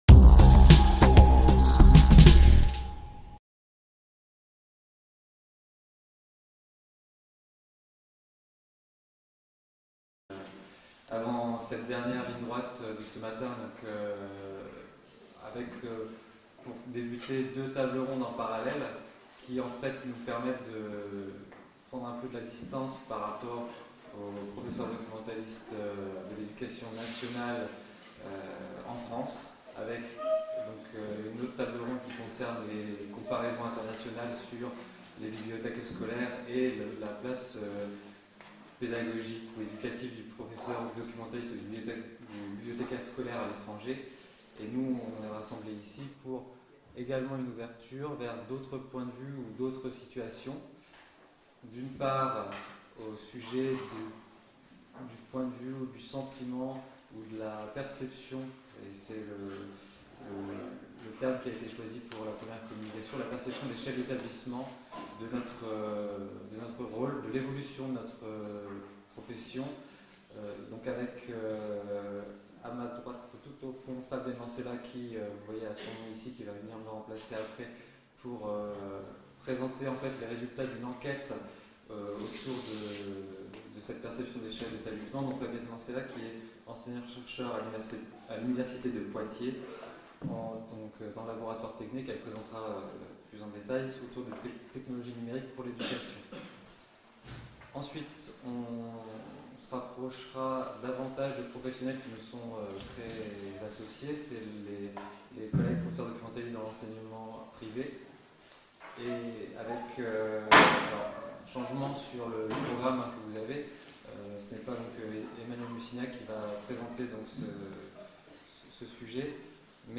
TABLE RONDE Animateur